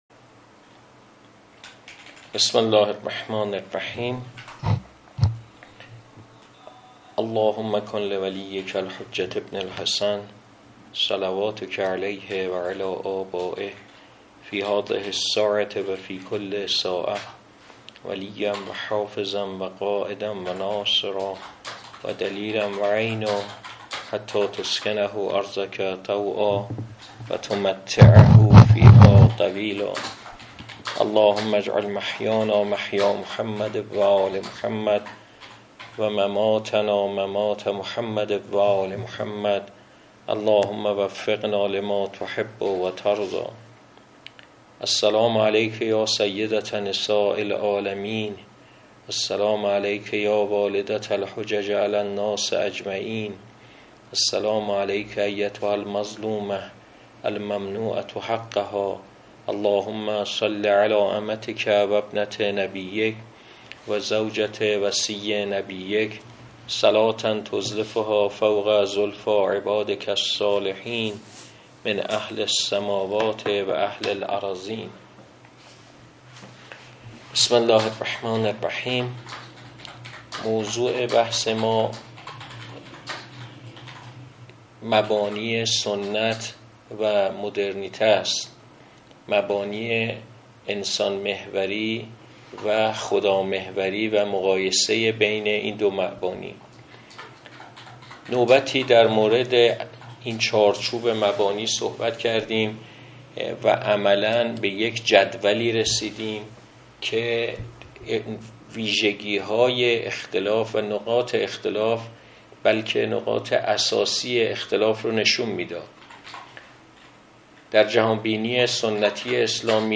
سخنرانی گردهمایی 15 آبان‌ماه 1404